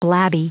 Prononciation du mot blabby en anglais (fichier audio)
Prononciation du mot : blabby